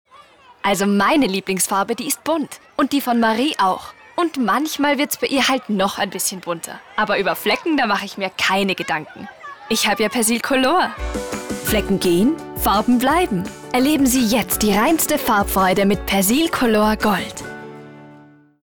Frau – ON